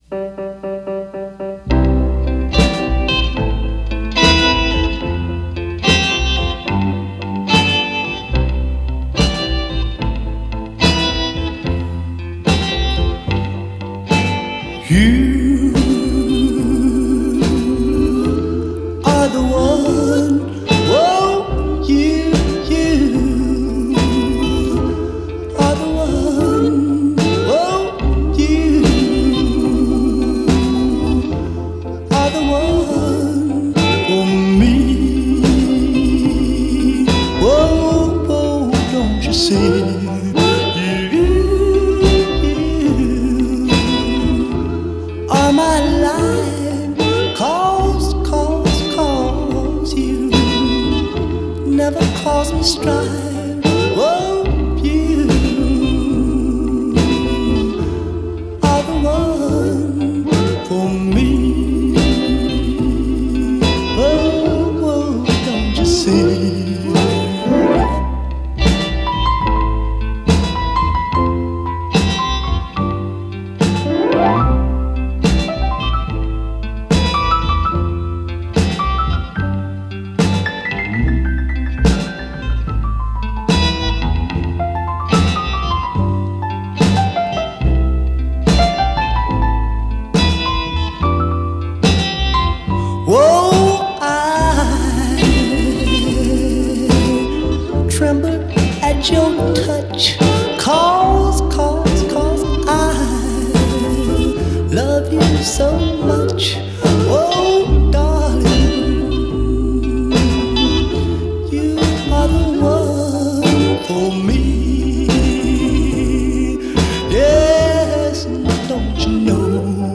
Жанр: Rock'n'Roll, Rockabilly